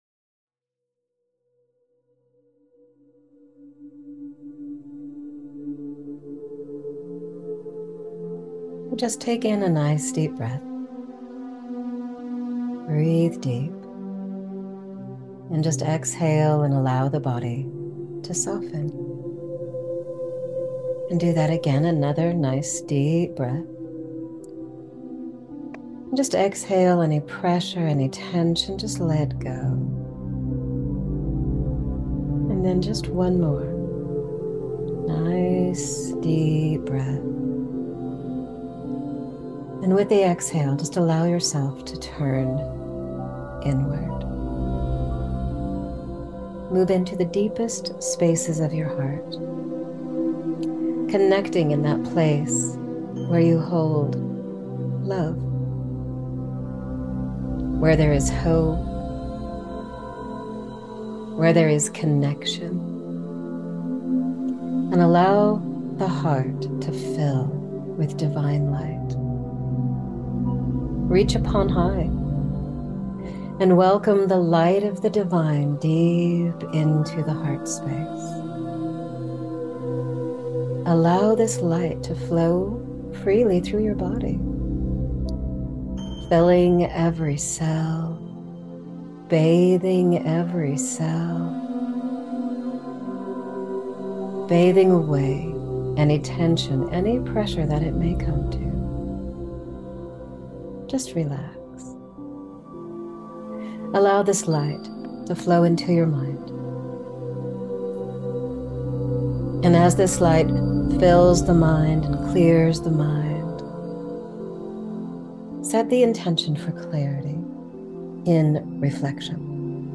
A self-hypnosis medition to anchor prosperity, empowerment, confidence, and clarity into a new beginning.